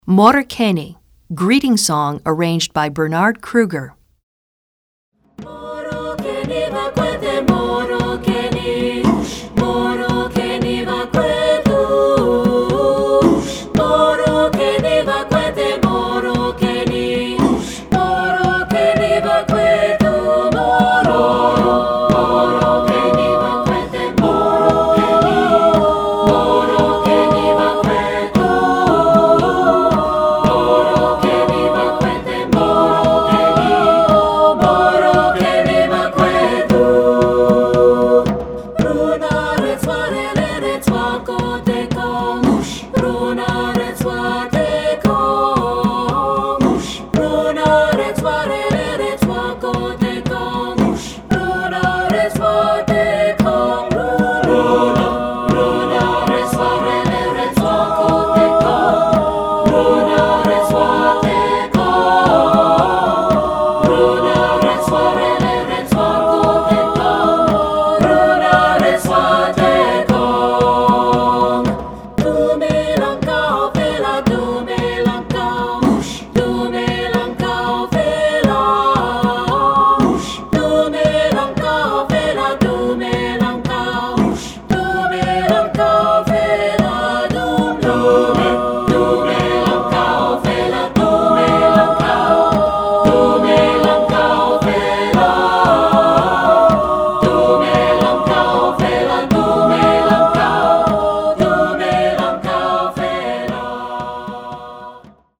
Choeur Mixte (SATB) a Cappella